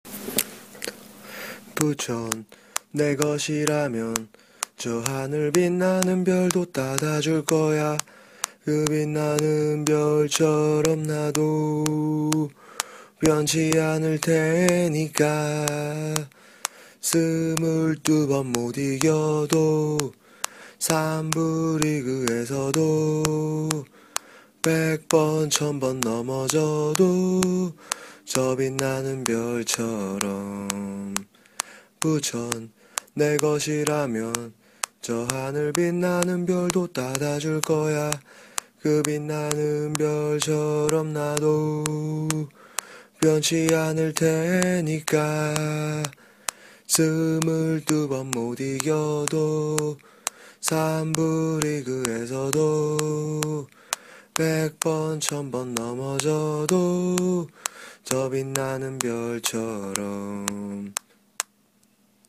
(야밤에 핸드폰 잡고 혼자 녹음 했으니 노약자 및 심장이 약하신 분들은 혼자 듣지 마시기 바랍니다)
<신규 응원가>